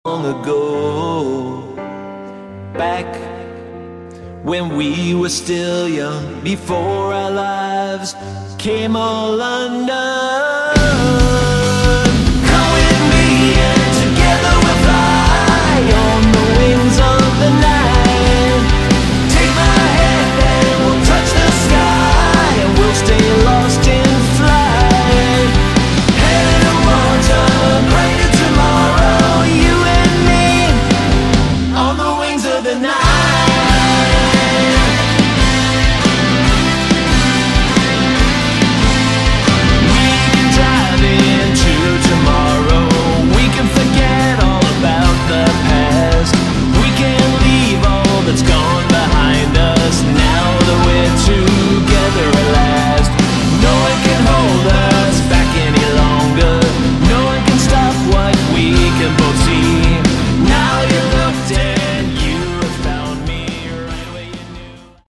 Category: Melodic Rock
vocals
bass
guitars
keyboards
drums